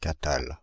Prononciation
Prononciation France (Île-de-France): IPA: /ka.tal/ Le mot recherché trouvé avec ces langues de source: français Traduction Contexte Substantifs 1.